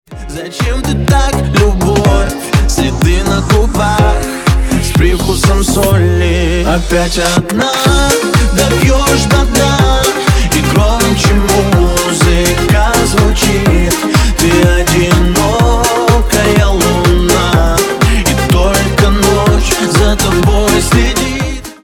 remix
Club House